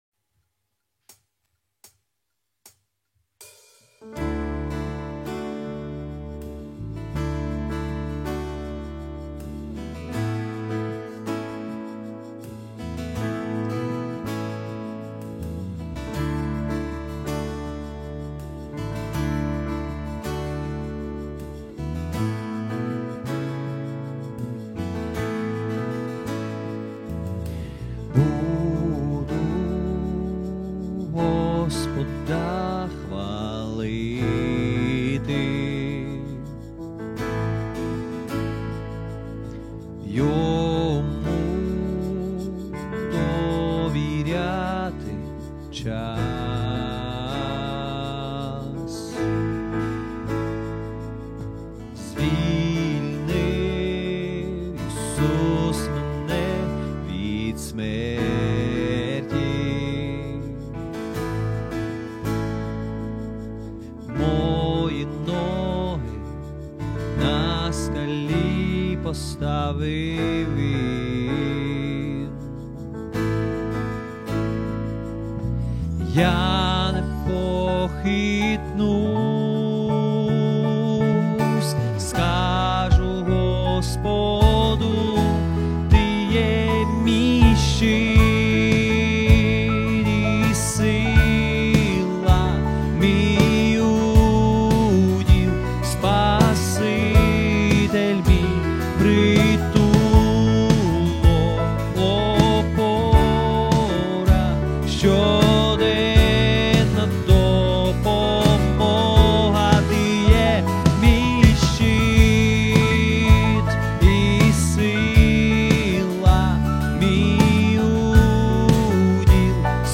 752 просмотра 419 прослушиваний 11 скачиваний BPM: 160